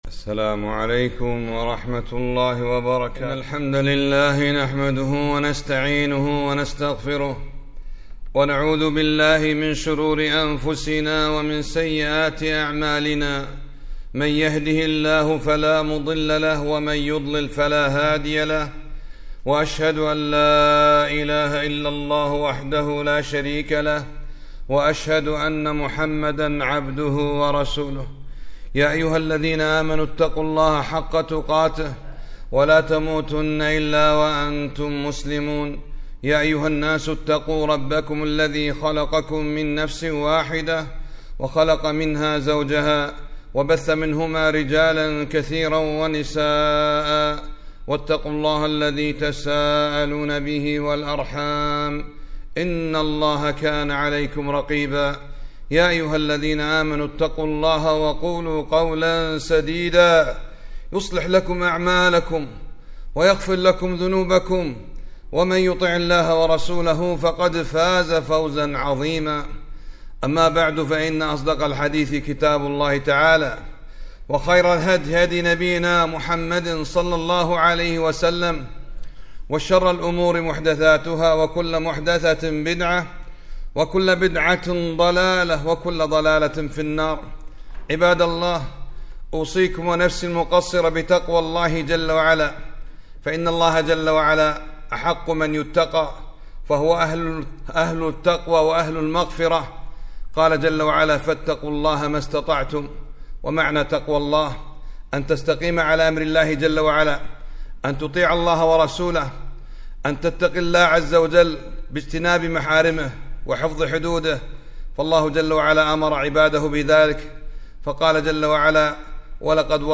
خطبة - قال النبي ﷺ ( من دعاء إلى هدى..)